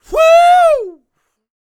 C-YELL 1102.wav